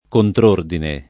[ kontr 1 rdine ]